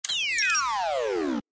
Звуки детонатора
Звук Вариант 2, отключение